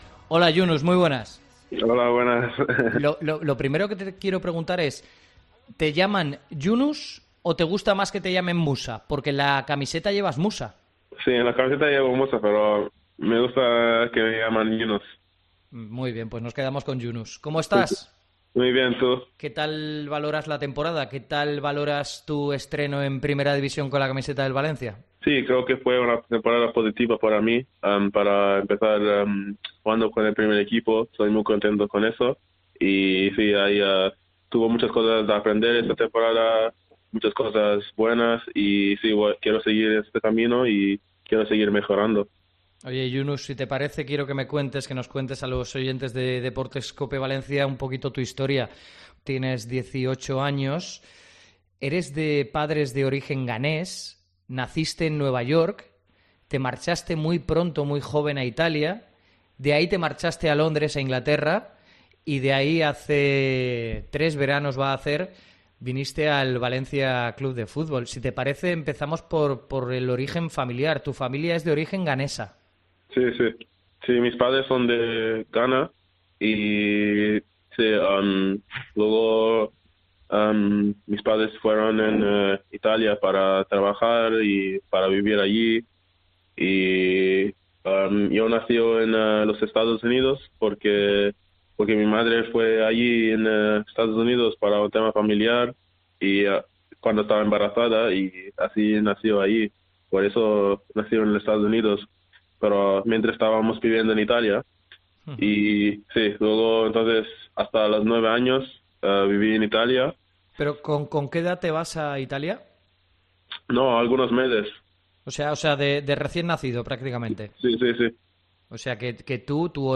AUDIO. Entrevista a Yunus en Deportes COPE Valencia